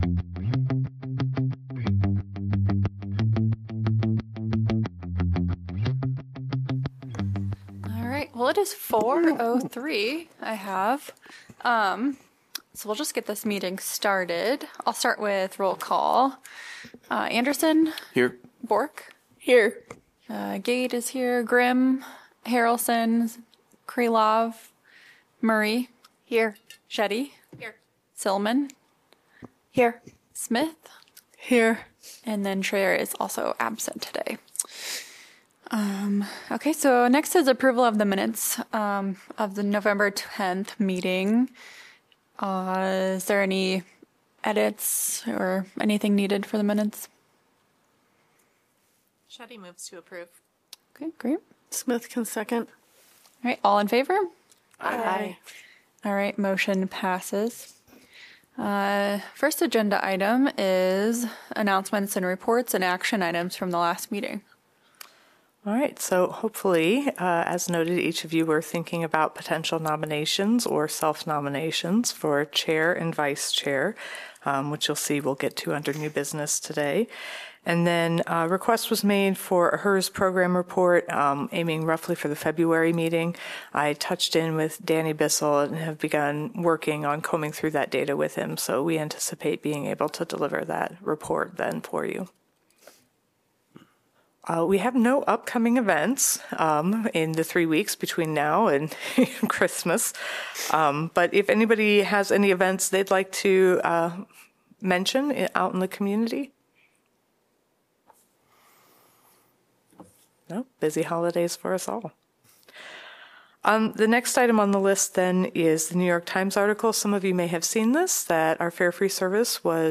Regular monthly meeting of the Climate Action Commission.